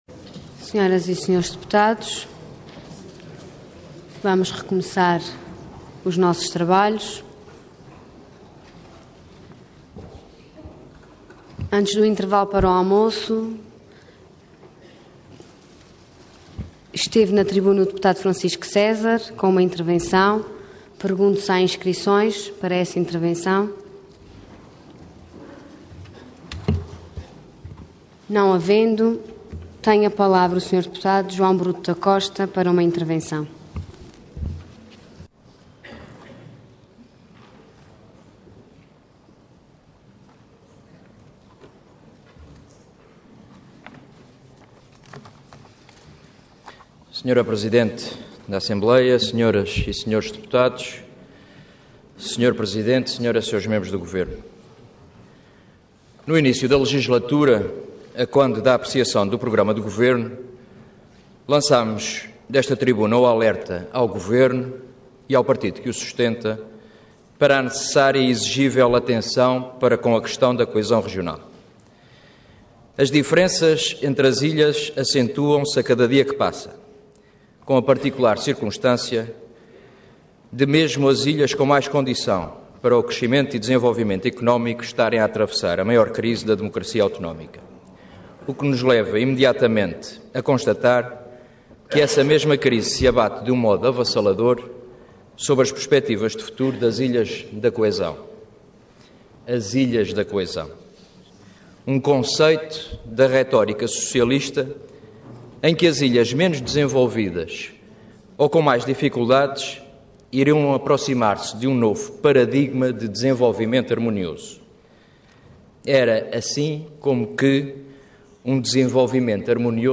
Intervenção Intervenção de Tribuna Orador João Bruto da Costa Cargo Deputado Entidade PSD